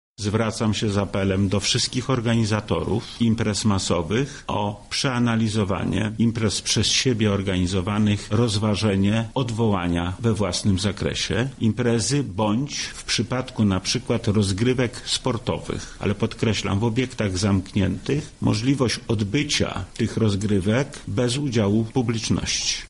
– mówi Wojewoda Lubelski, Lech Sprawka